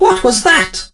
barley_hurt_01.ogg